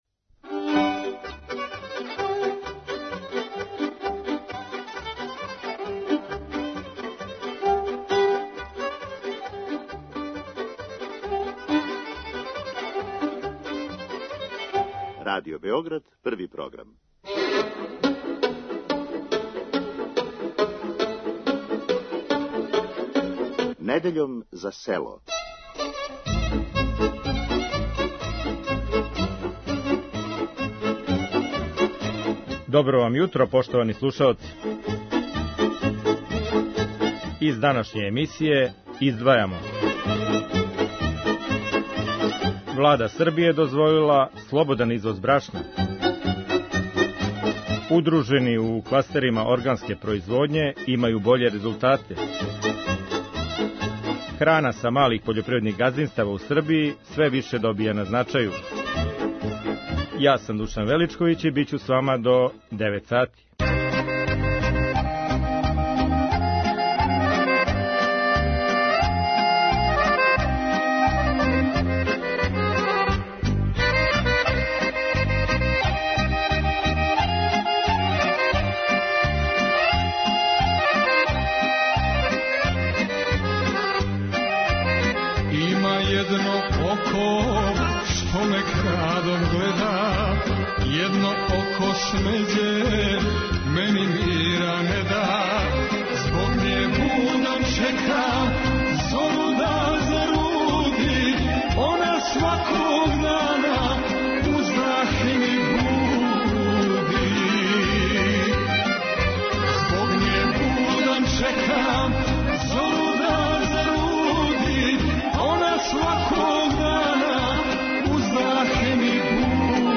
За Недељом за село говоре произвођачи из Војвођанског кластера органске пољопривреде.